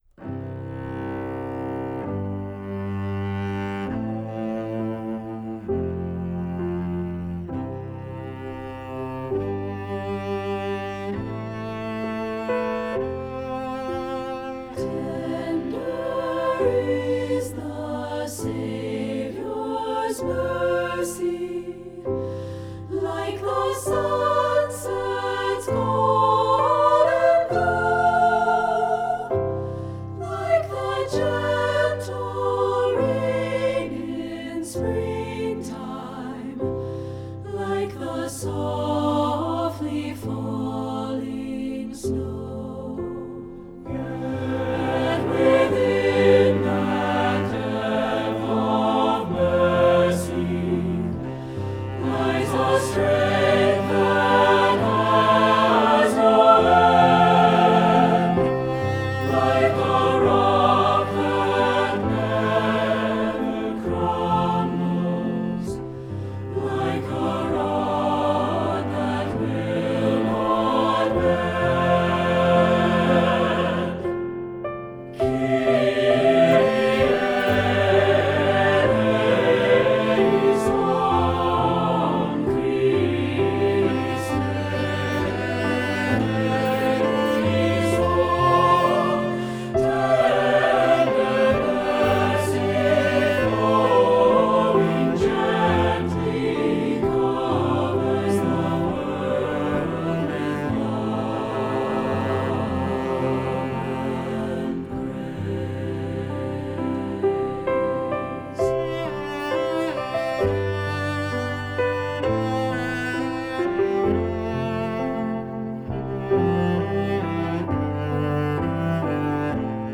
Voicing: SATB, Piano and Optional Cello